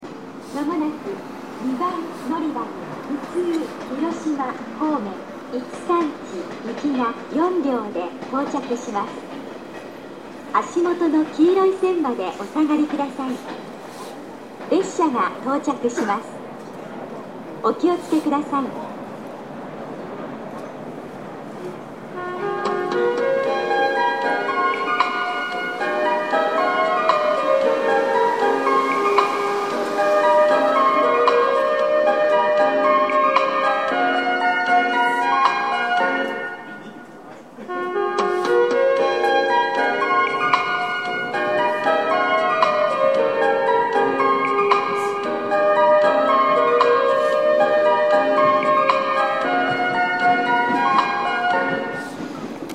この駅では広島支社の詳細型の接近放送が設置されております。スピーカーも設置されており位置が低めなので収録がしやすいです。音量も大きめだと思いますね。
接近放送普通　広島方面　五日市行き接近放送です。